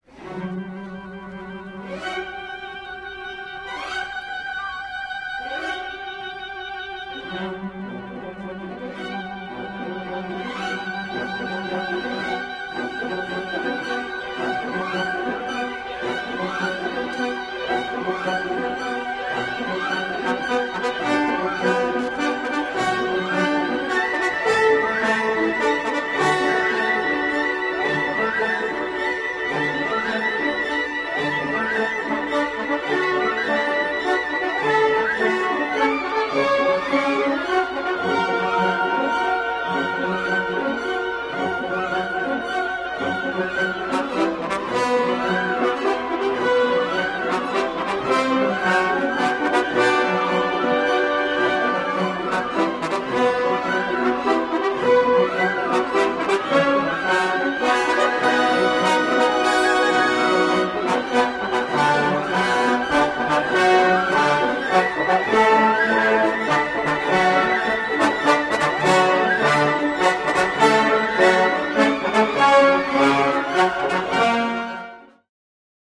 © James Nachtwey and Richard Wagner: Die Walküre (The Valkyrie), “The Ride of the Valkyries.” Performed by the San Francisco Opera Orchestra, conducted by Donald Runnicles
There is an electrifying buzz of excitement in the air as Wotan’s warrior-maidens swoop across the sky on their way to collect fallen heroes from the battlefield, soaring above as in the gravity-defying somersaults of these performers.